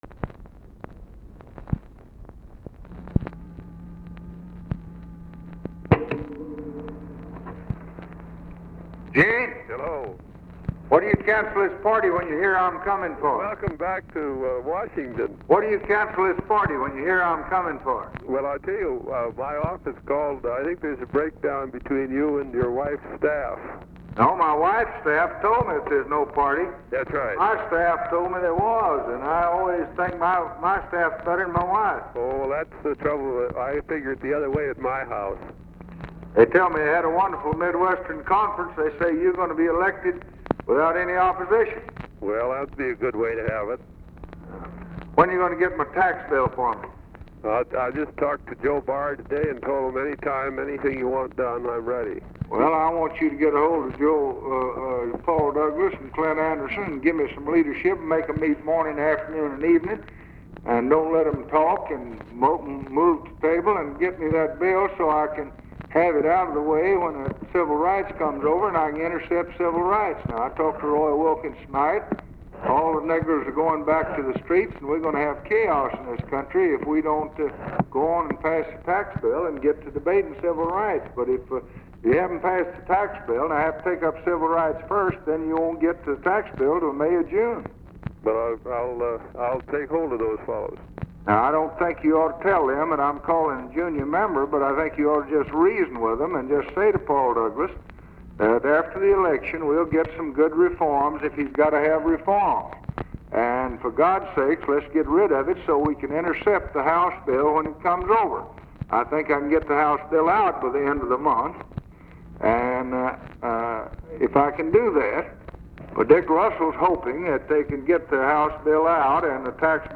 Conversation with EUGENE MCCARTHY, January 7, 1964
Secret White House Tapes